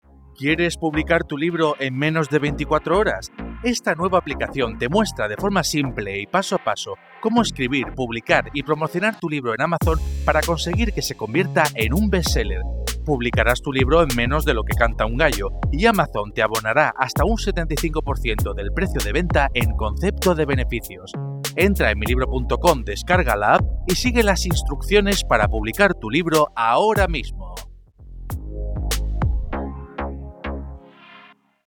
HOMBRES (de 35 a 50 años)